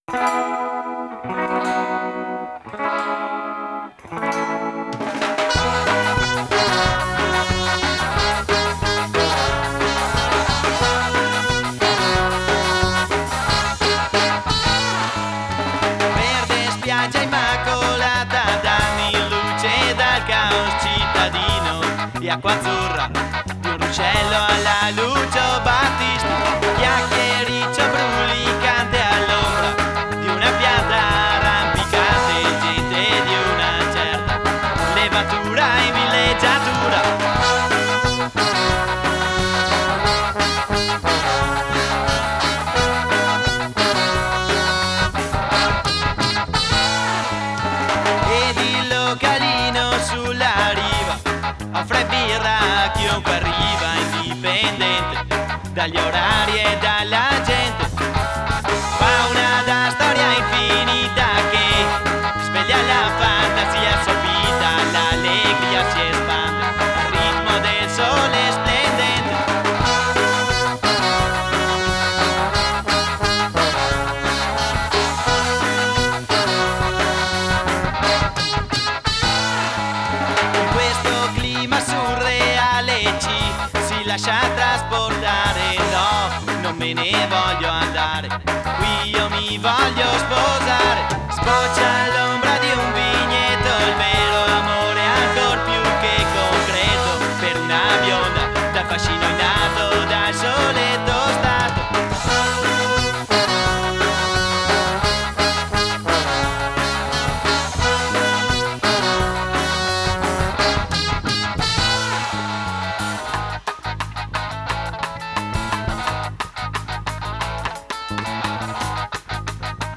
Categoria: Demo